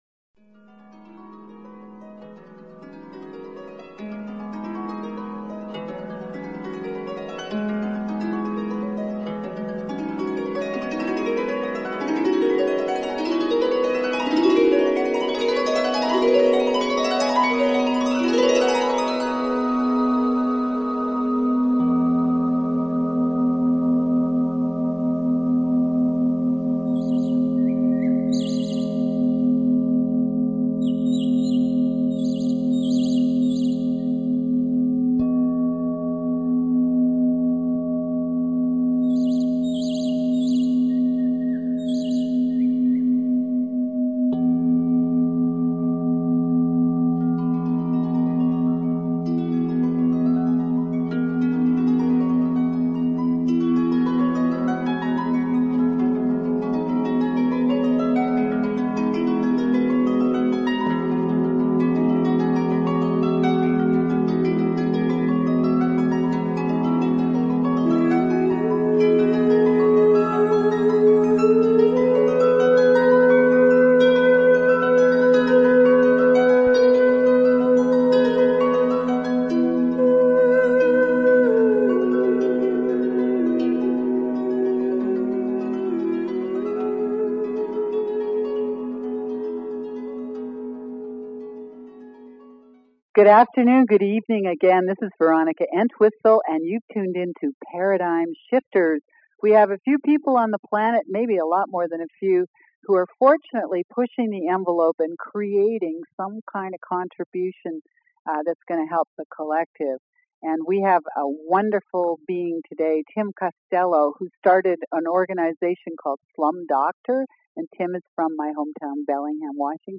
This interview is rich.